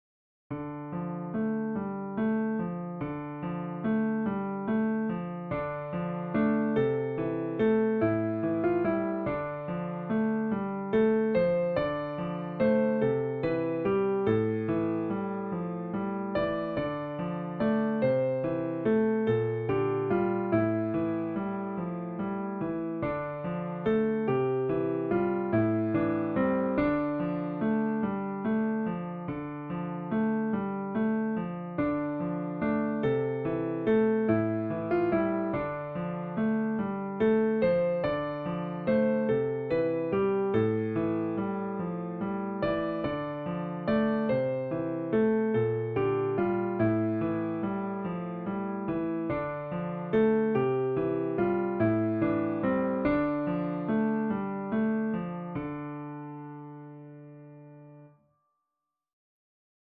traditional English folk song or ballad
for piano